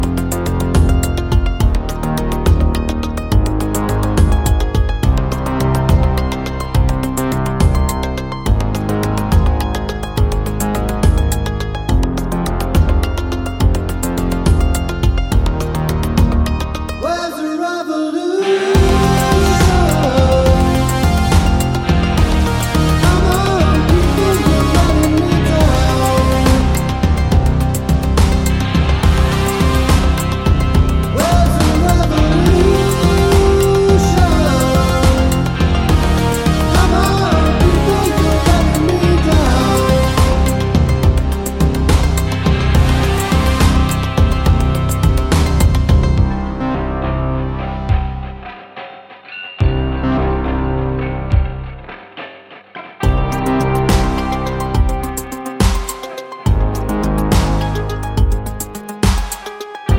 Professional Backing Tracks